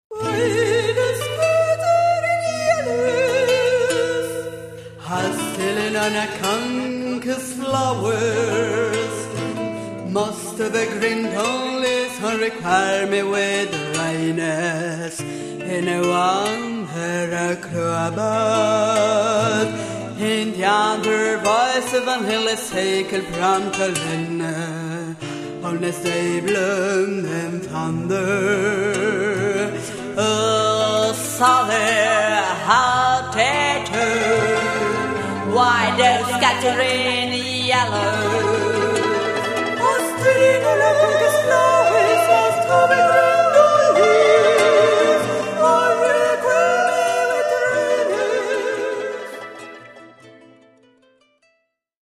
Romanza